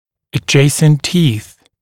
[ə’ʤeɪsnt tiːθ][э’джейснт ти:с]соседние зубы